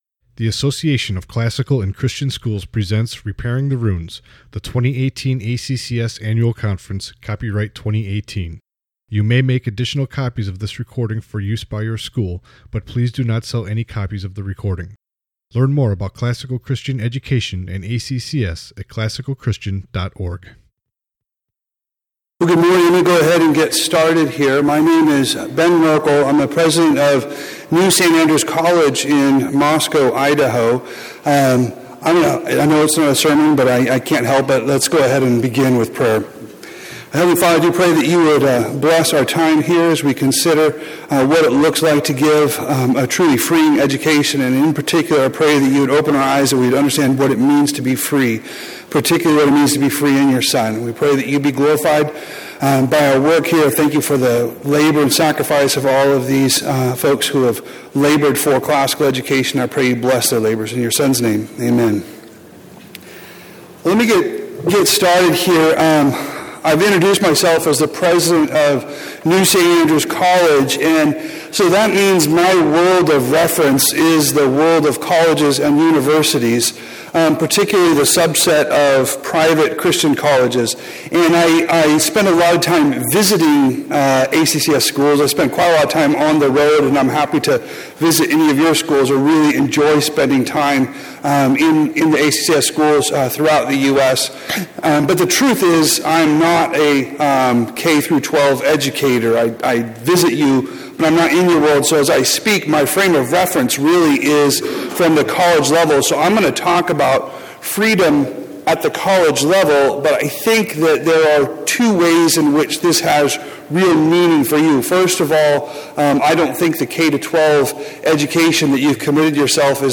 2018 Workshop Talk | 1:00:12 | All Grade Levels, Culture & Faith